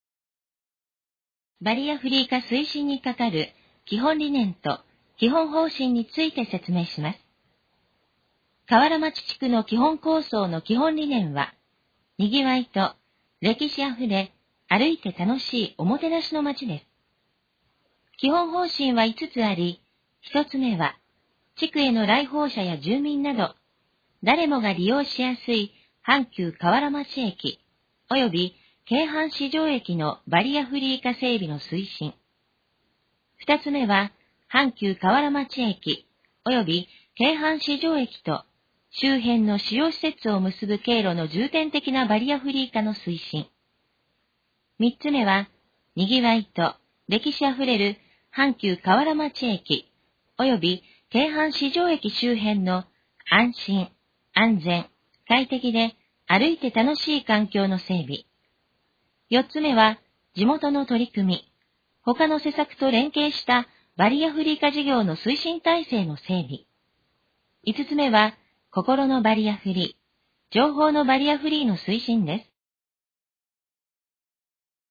このページの要約を音声で読み上げます。
ナレーション再生 約146KB